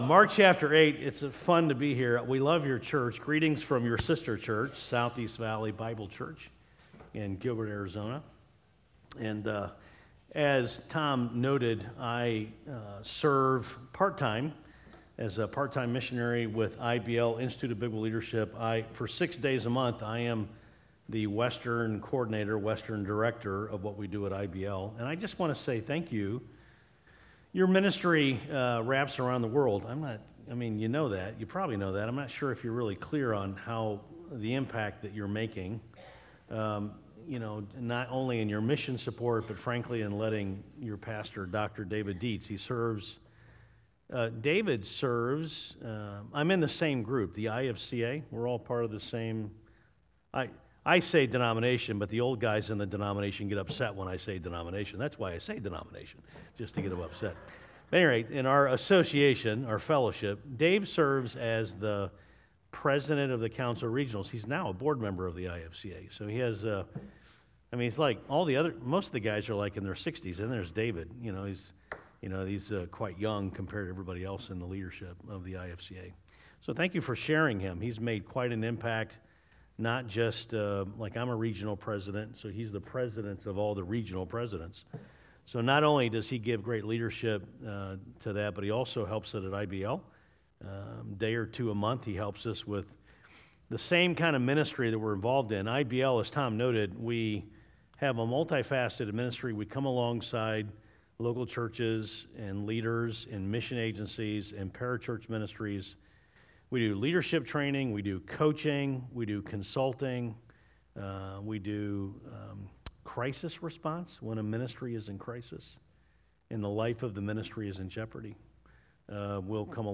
2018 Guest Speaker https